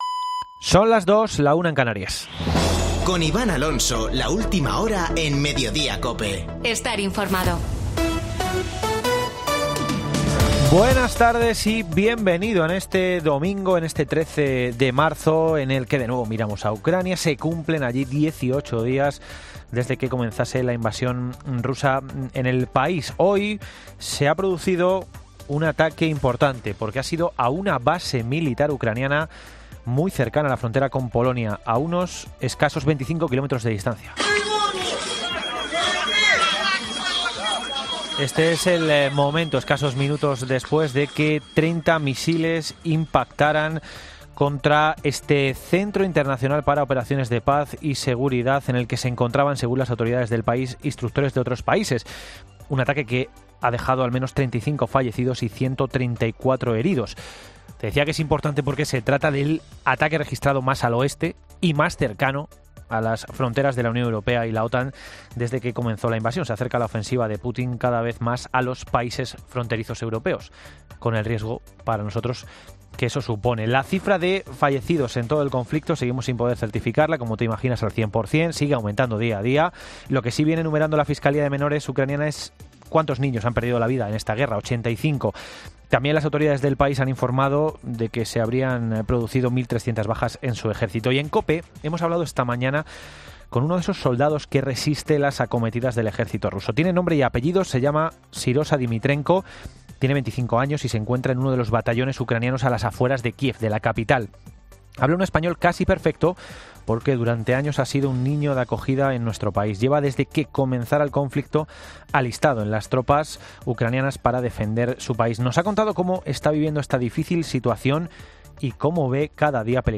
Boletín de noticias COPE del 13 de marzo de 2022 a las 14.00 horas